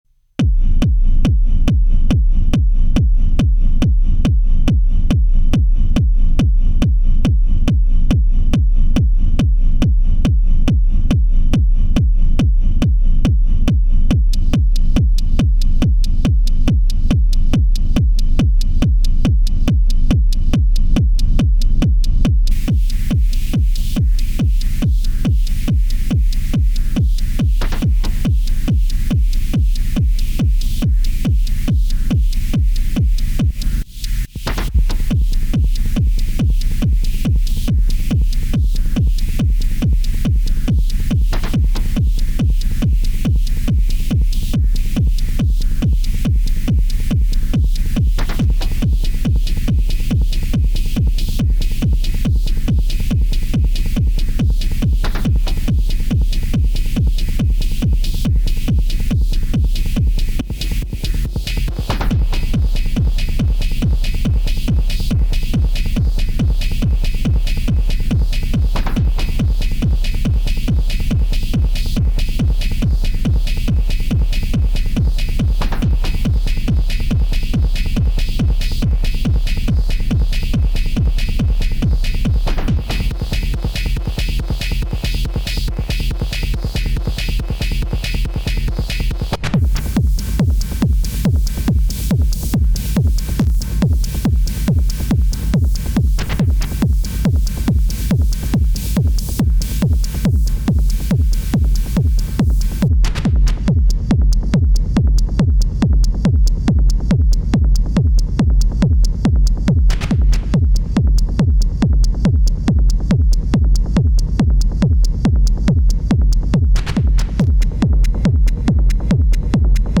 The 1:1 pass-through stereo audio signal is attenuated by the VCA using a curve that is triggered by the trigger input. Two controls for intensity and shape length allow for adjustment of the curve, making ducking effects easy to implement.
>>SIDECHAINER SOUND EXAMPLE (AMPERE)